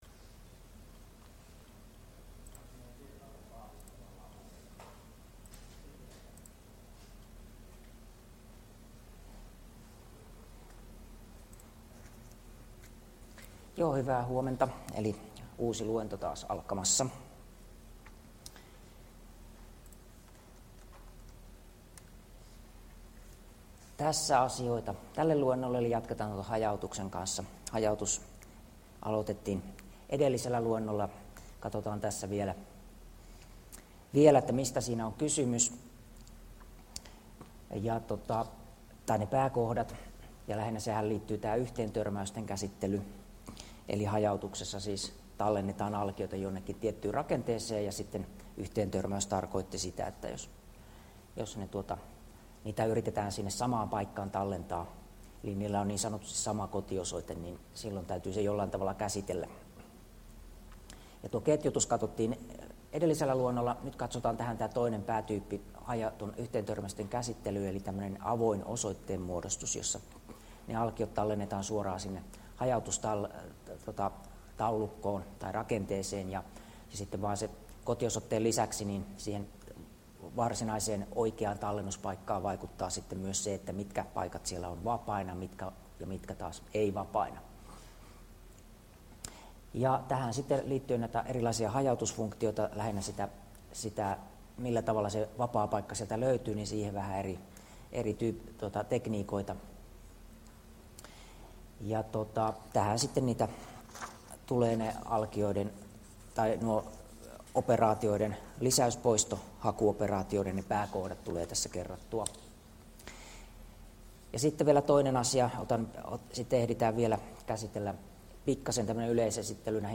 Luento 4 — Moniviestin